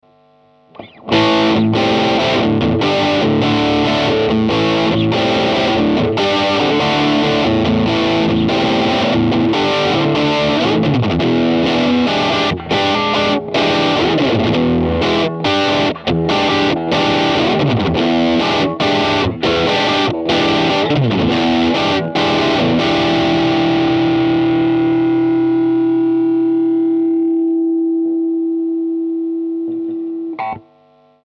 3. Godin Triumph Sparkle Blue Drive 736,37 Кб
Драйв на бриджевом сингле
godin_triumph_drive.mp3